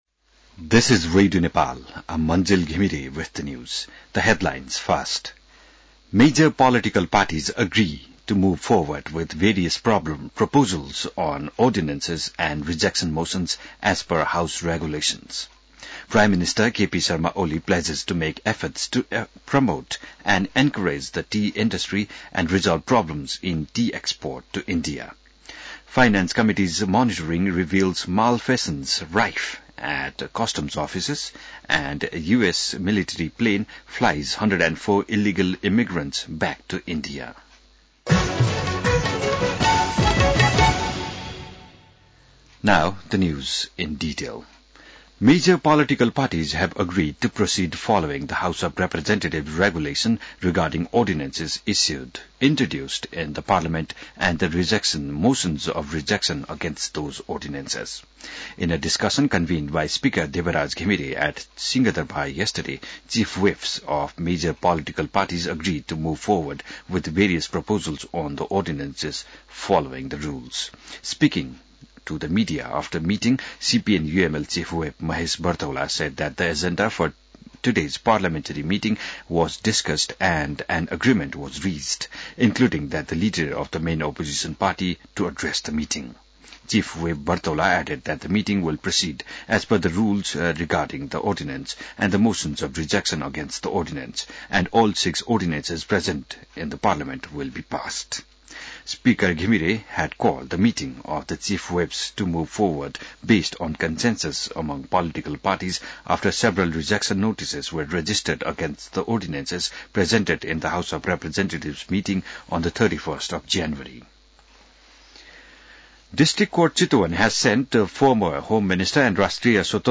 बिहान ८ बजेको अङ्ग्रेजी समाचार : २५ माघ , २०८१